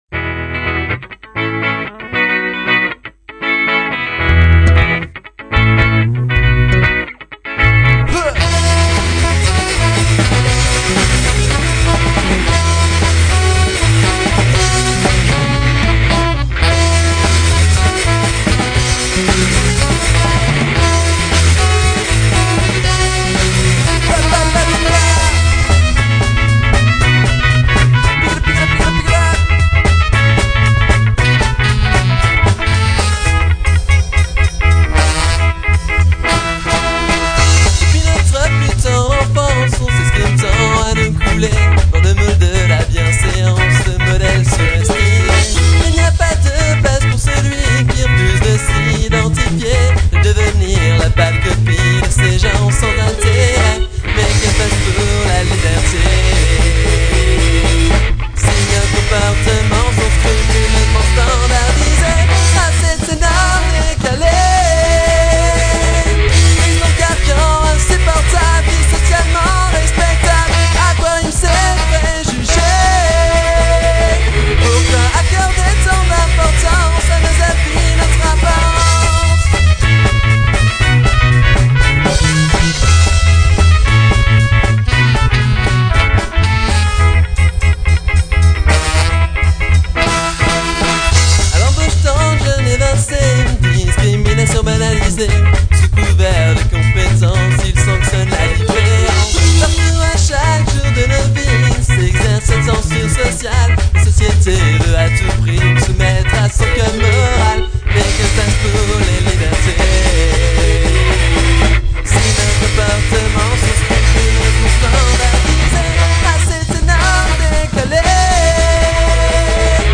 ska-punk